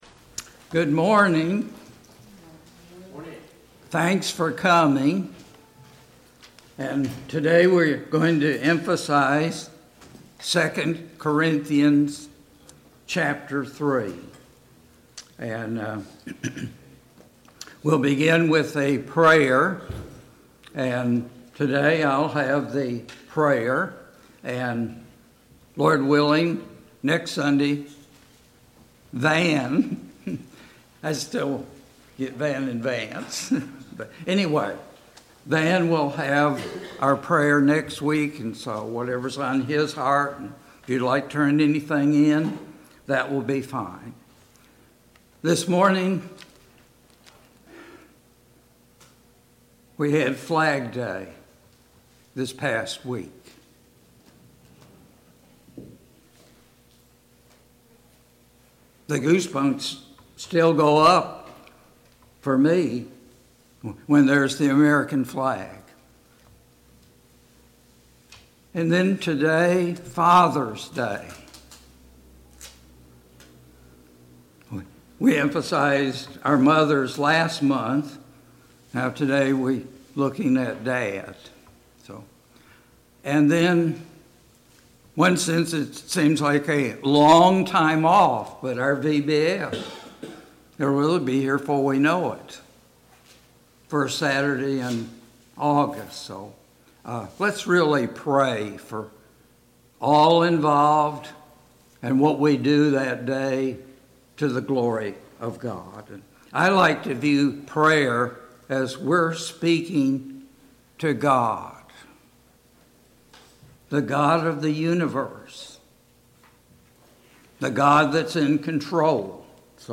A Study of 2 Corinthians Passage: 2 Corinthians 3 Service Type: Sunday Morning Bible Class « A Study of Philemon 3.